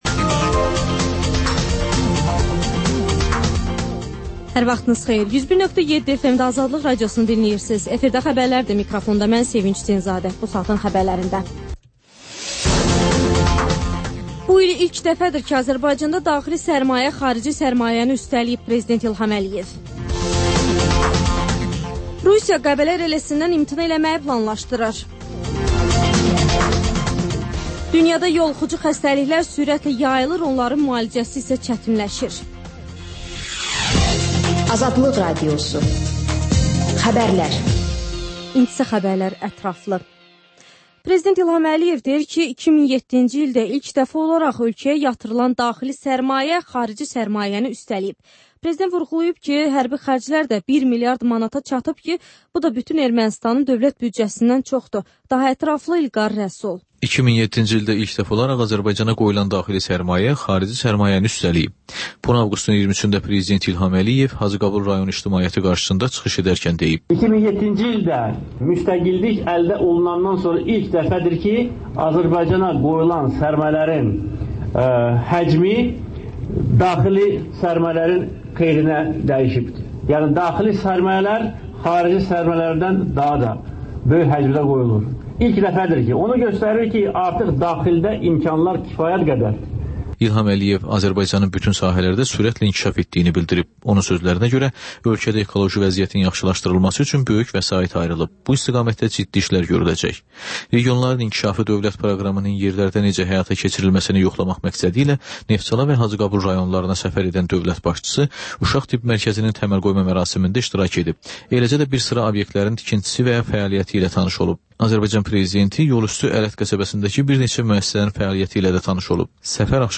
Xəbərlər, müsahibələr, hadisələrin müzakirəsi, təhlillər